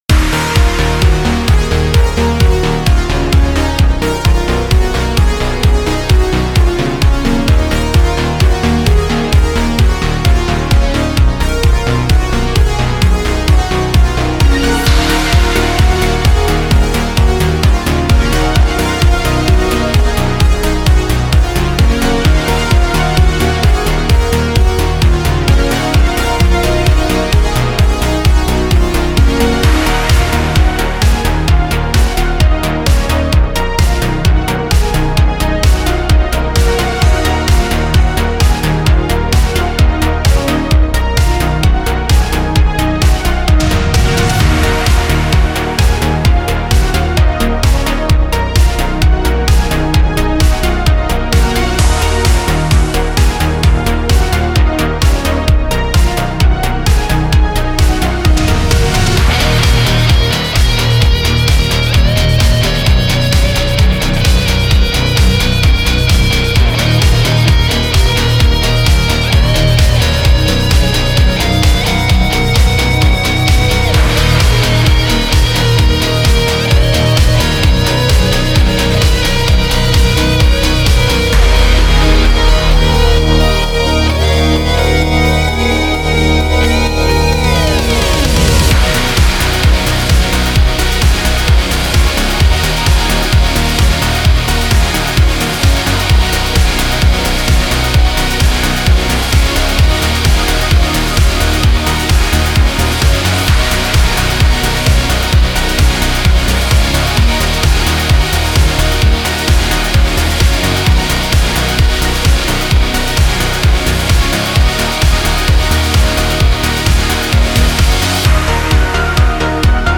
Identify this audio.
Genre: Spacesynth.